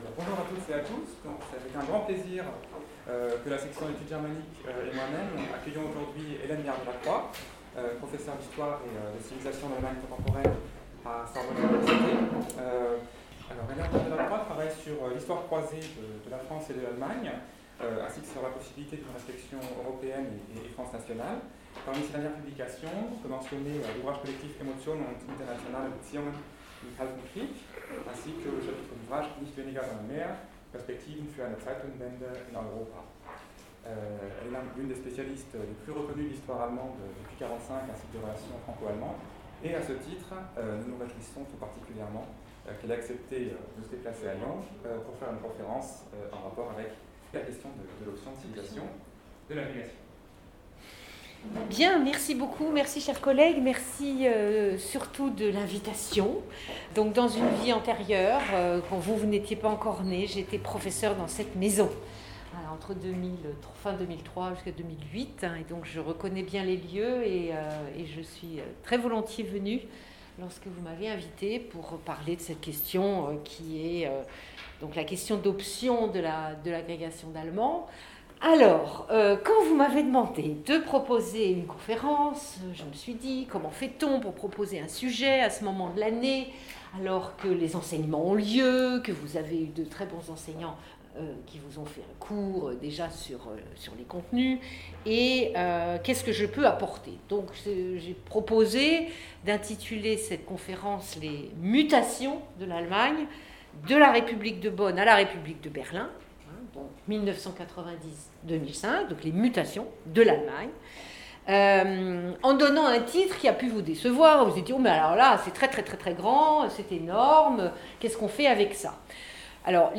[Conférence]